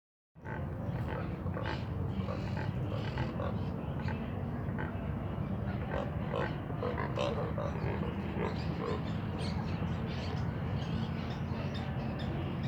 Biguá (Nannopterum brasilianum)
Nombre en inglés: Neotropic Cormorant
Localización detallada: Parque Sarmiento
Condición: Silvestre
Certeza: Observada, Vocalización Grabada
Bigua_1.mp3